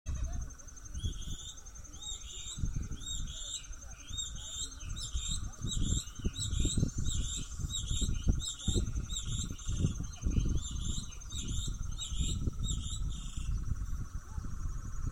Plumbeous Rail (Pardirallus sanguinolentus)
Location or protected area: Santa María
Condition: Wild
Certainty: Recorded vocal